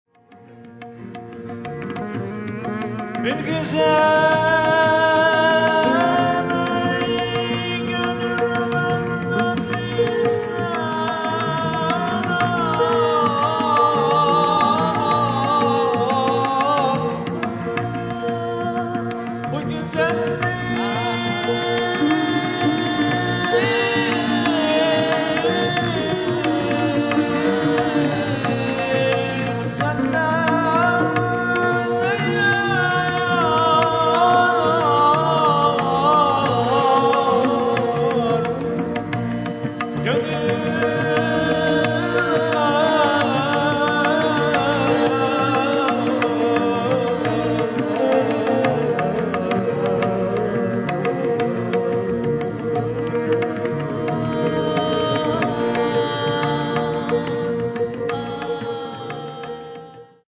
Bhajan-based jam
2 x vocals, violin, sitar, double bass, tabla